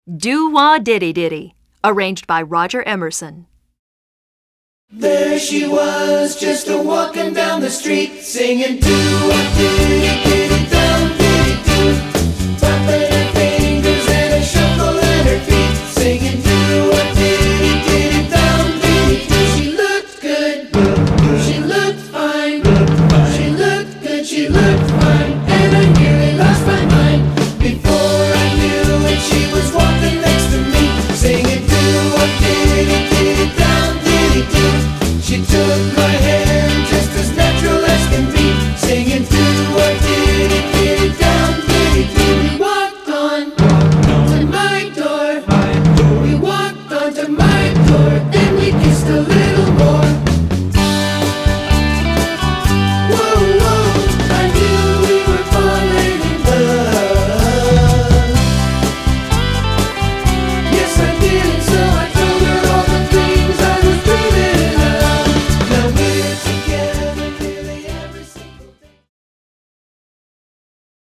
Voicing: TB